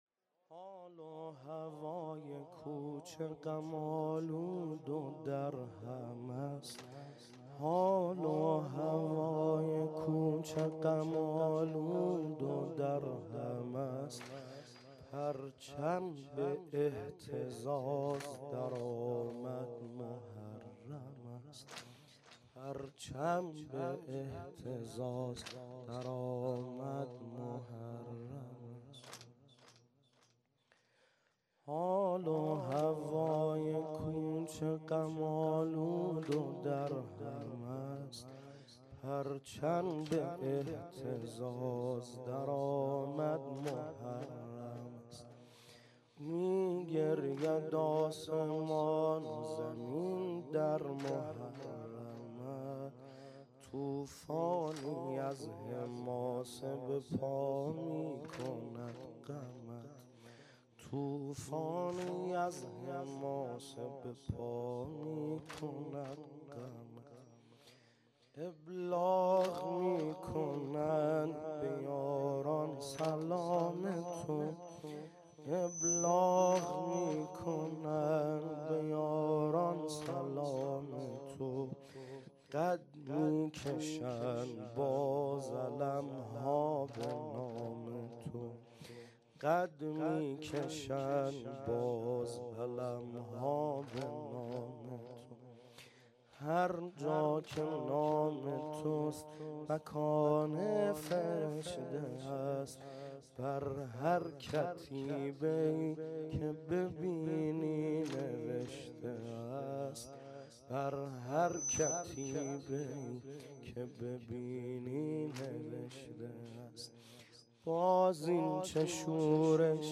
شب اول محرم 1396